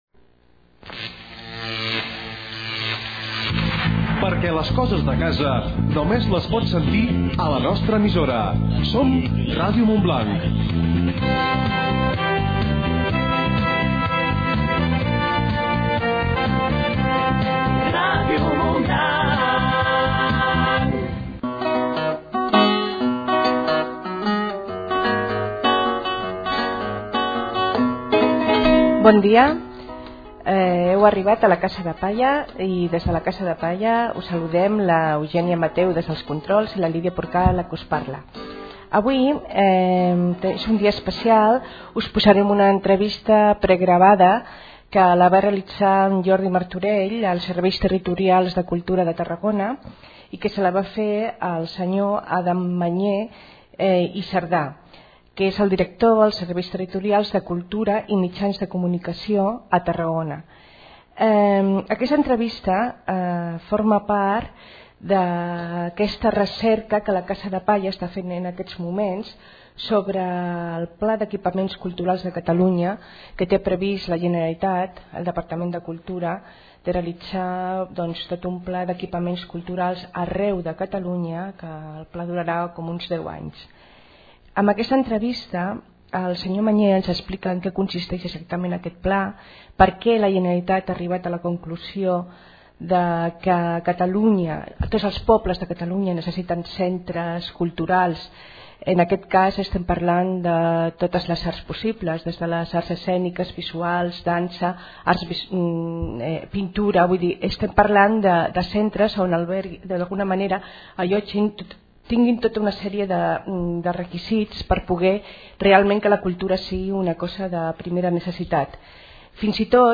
Entrevista a Adam Manyé, Director dels Serveis Territorials de Cultura i Mitjans de Comunicació de Tarragona.
Escolta-lentrevista-amb-Adam-Manyè.mp3